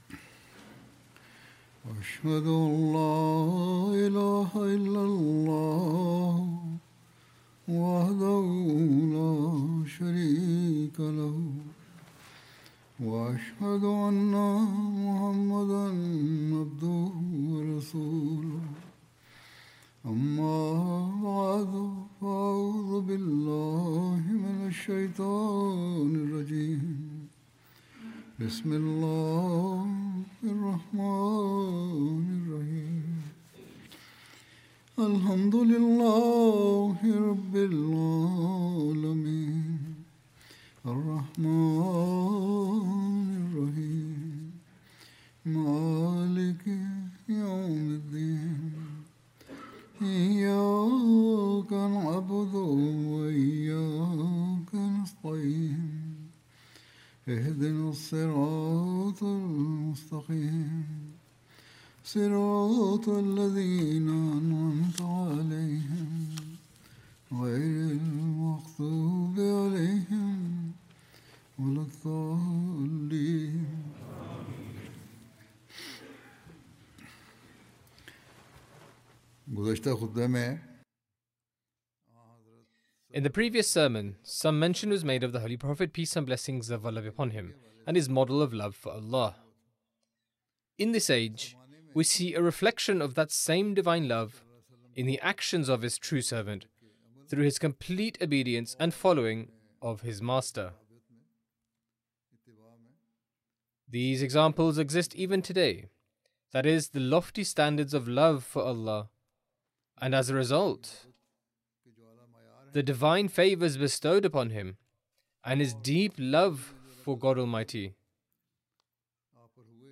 English translation of Friday Sermon (audio)